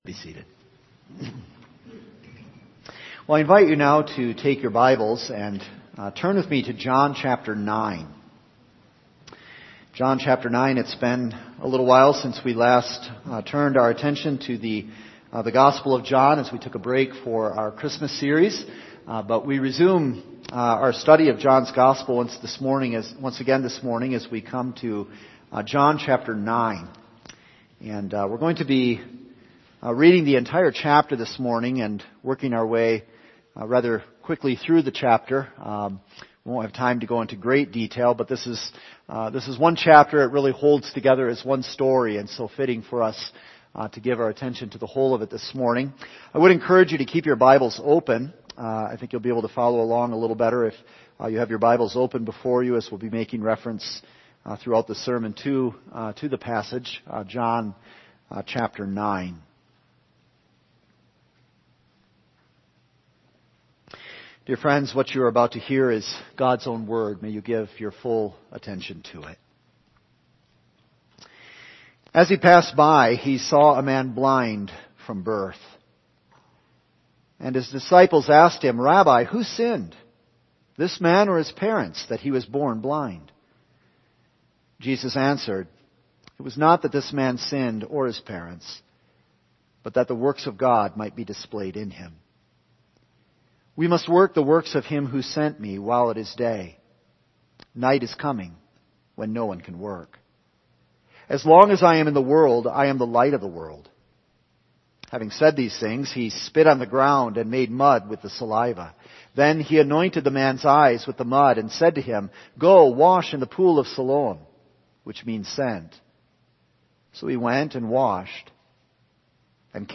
All Sermons The Light of the World